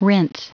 Prononciation du mot : rinse
rinse.wav